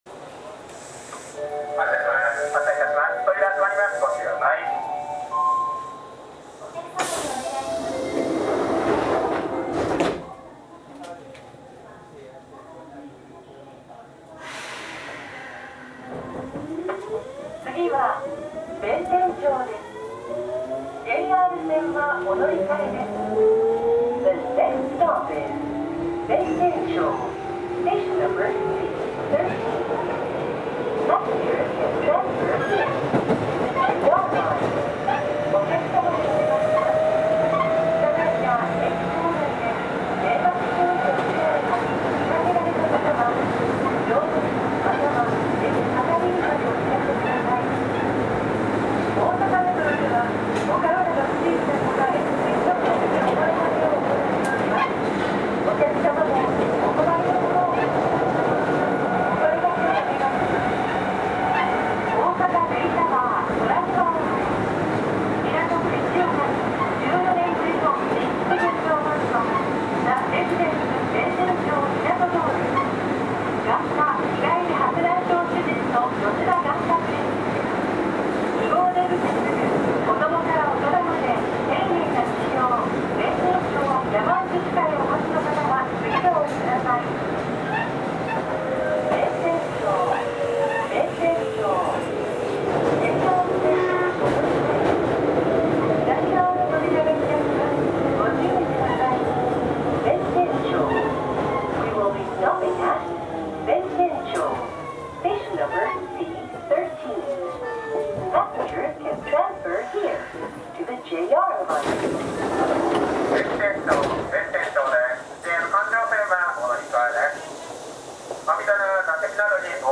走行機器はGTO素子によるVVVFインバータ制御で、定格140kWのMB-5011-A形かご形三相誘導電動機を制御します。
走行音
録音区間：九条～弁天町(お持ち帰り)